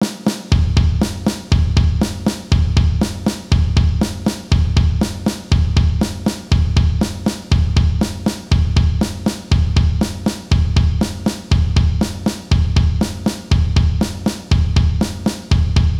Tempo des sons : 60
Sur le même principe que l’exercice précédant, vous allez enchaîner les coups de caisse claire et de grosse caisse. Sauf que vous devrez jouer des doubles croches et enchaîner deux coup successifs sur chaque élément, pas si facile que ça !